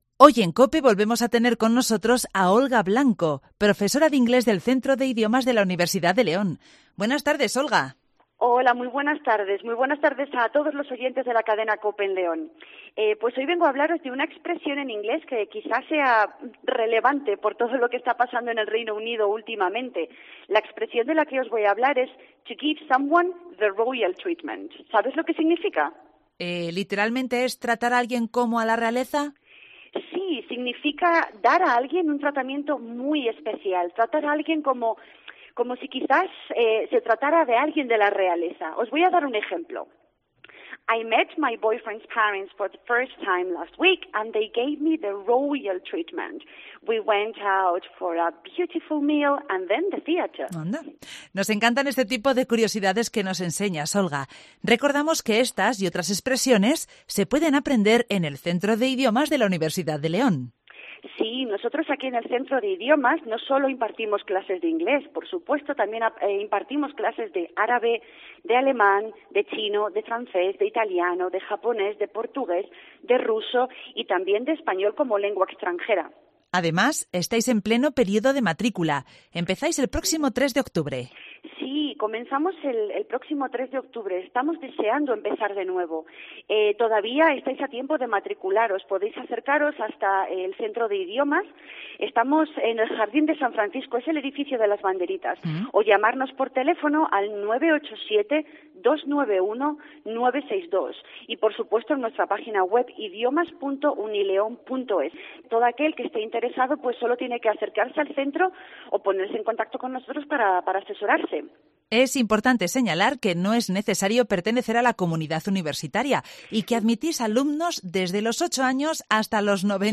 En Directo COPE LEÓN